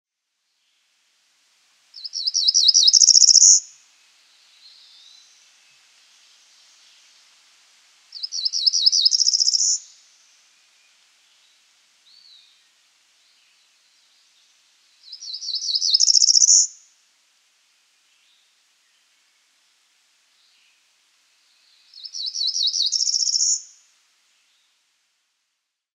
Cerulean Warbler
BIRD CALL: THREE-PARTED, LOW-PITCHED BUT RISING AND ACCELERATING “TEW-TEW-TEWCHEE-CHEE-CHEE-BYZZ.”
Cerulean-warbler-call.mp3